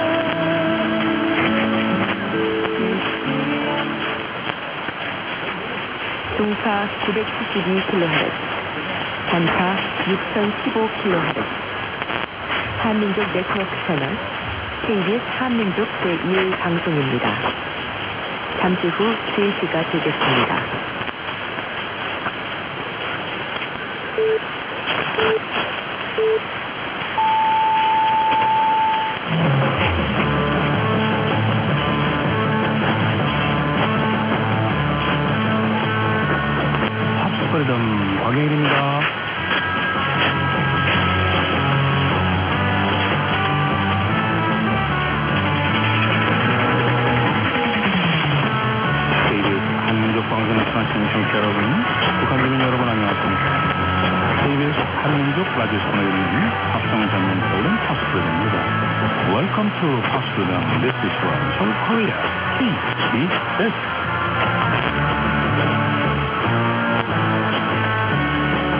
Heard this morning at the Grayland Motel with 160’ DKAZ ‘aiming’ about 290 deg. with 900 Ohm Rt.
Almost 3 hours after sunrise!